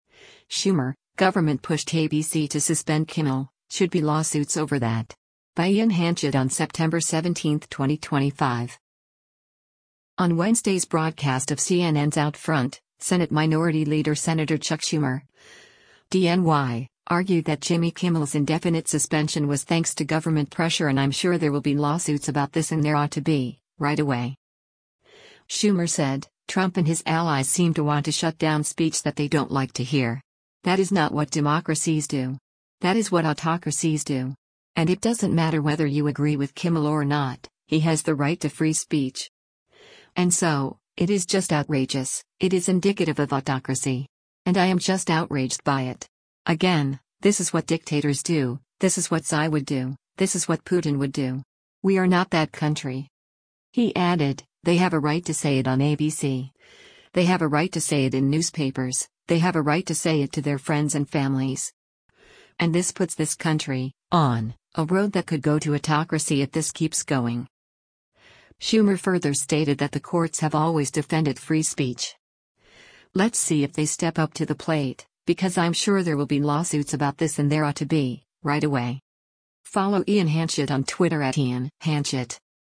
On Wednesday’s broadcast of CNN’s “OutFront,” Senate Minority Leader Sen. Chuck Schumer (D-NY) argued that Jimmy Kimmel’s indefinite suspension was thanks to government pressure and “I’m sure there will be lawsuits about this and there ought to be, right away.”